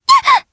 Selene-Vox_Damage_jp_01_b.wav